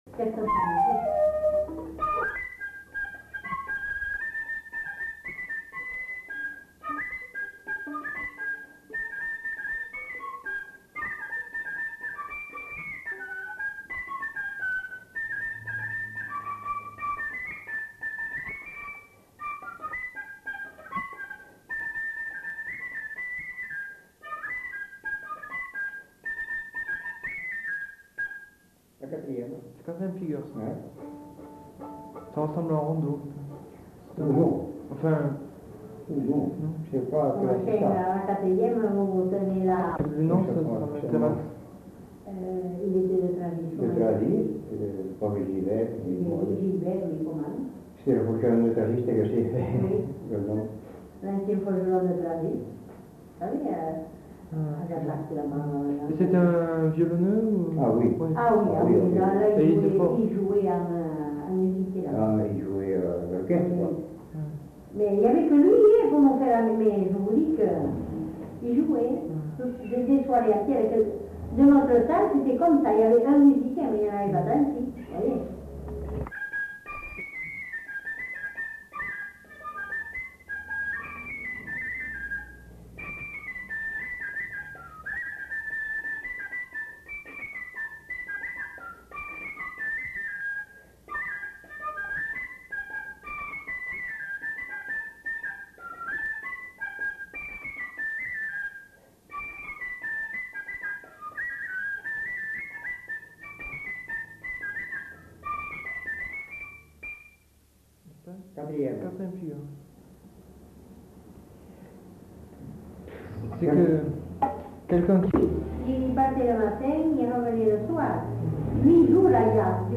Aire culturelle : Bazadais
Lieu : Bazas
Genre : morceau instrumental
Instrument de musique : fifre
Danse : quadrille (4e f.)